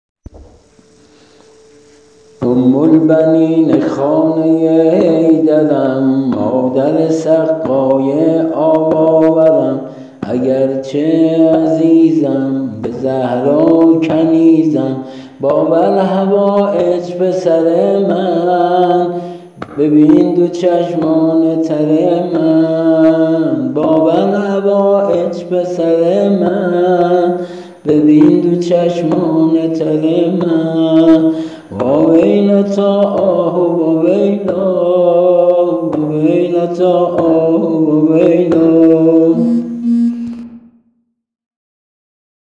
◾سبک سنتی